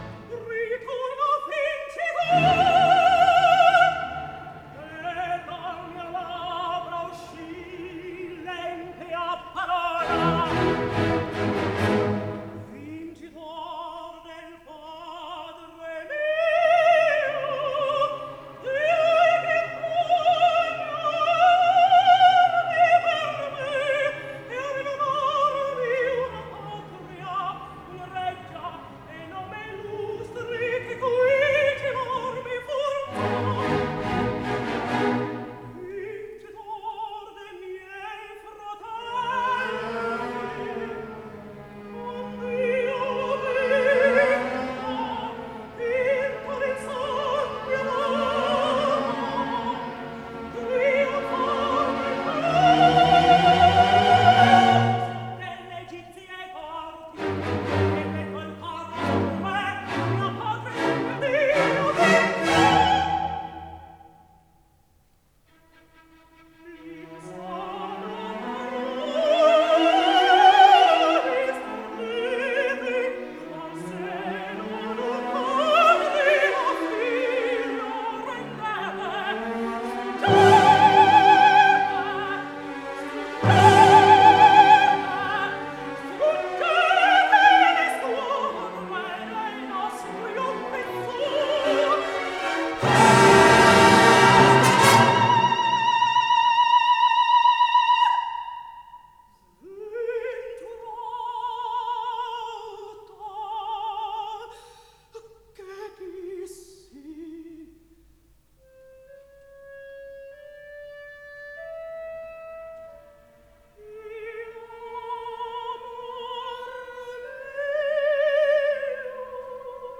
soprano drammatico